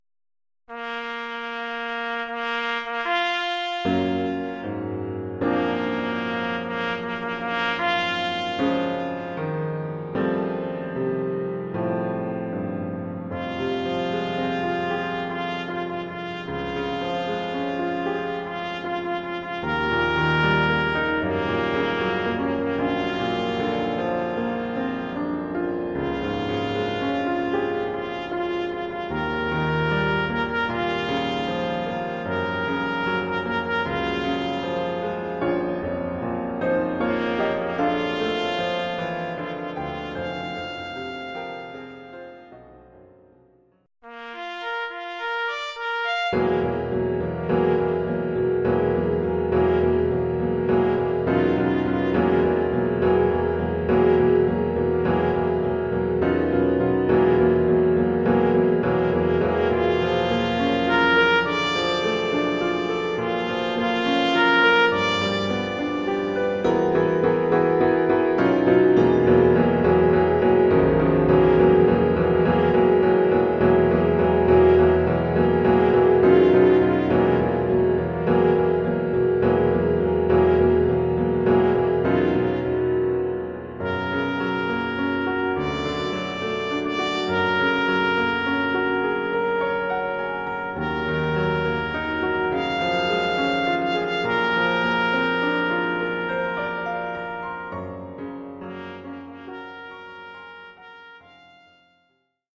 Oeuvre pour clairon ou clairon basse sib et piano.
clairon basse sib et piano.